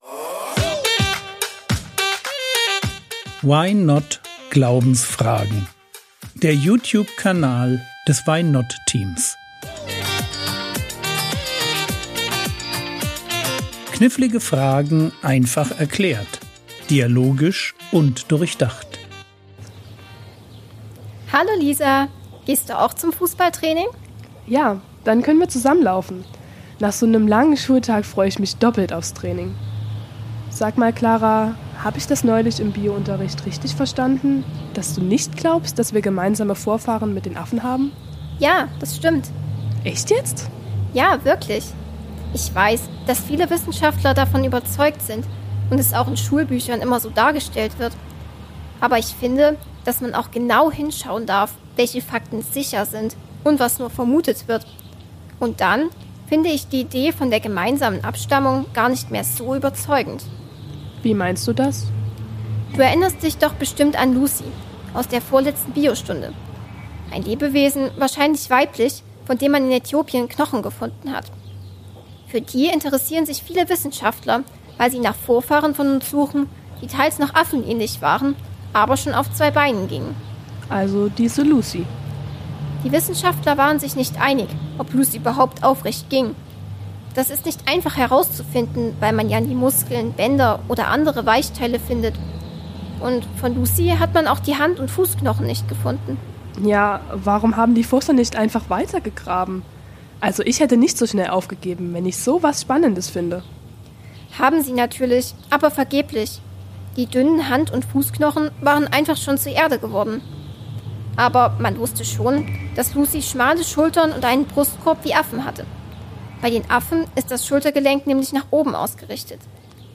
~ Frogwords Mini-Predigt Podcast